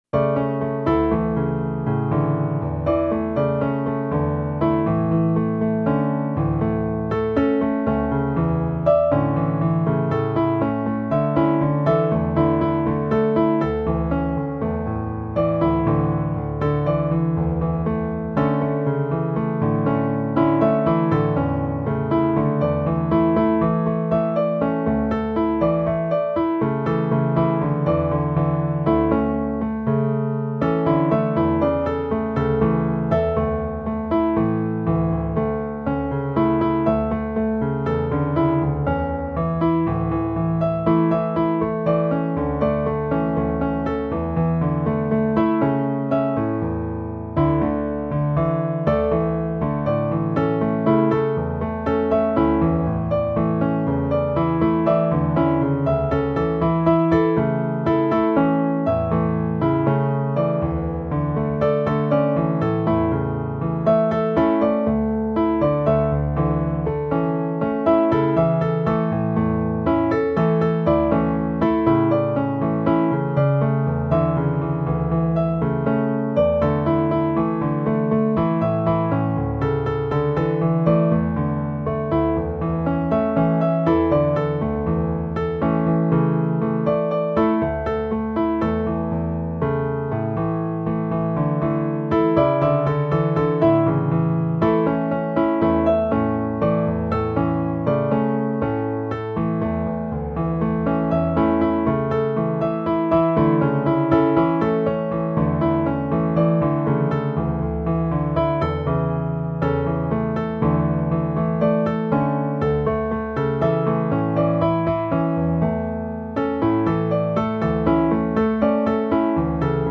instrumental alert track: